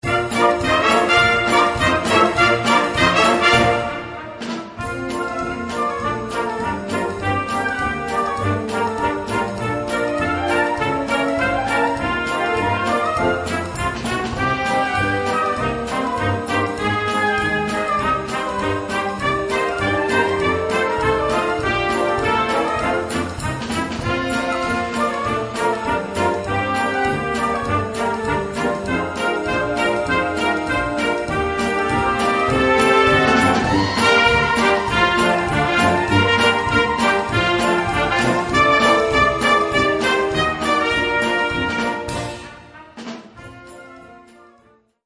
Gattung: Swing
Besetzung: Blasorchester
Das swingende Werk im Dixieland-Stil